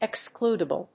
/ɪˈkskludʌbʌl(米国英語), ɪˈksklu:dʌbʌl(英国英語)/